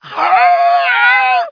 zombie_scream_1.wav